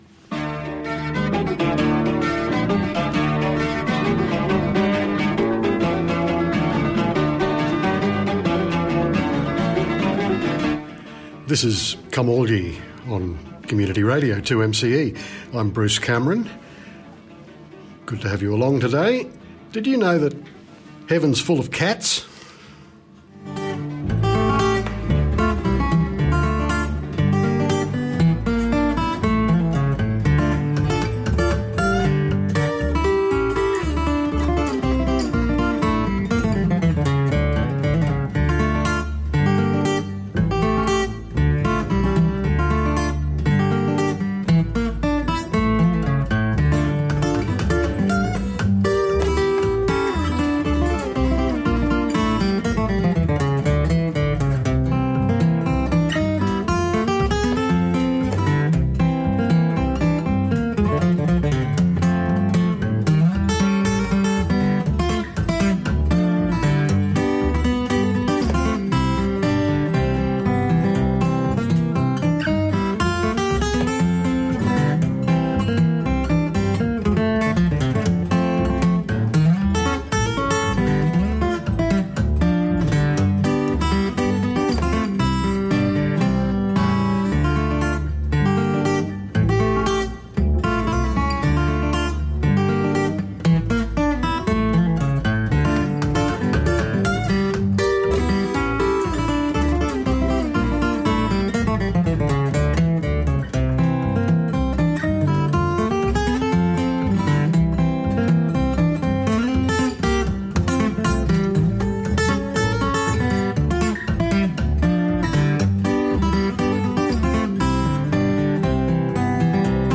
It holds together nicely, utilising not much more than fiddle, guitar and occasional vocals and percussion.